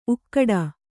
♪ ukkaḍa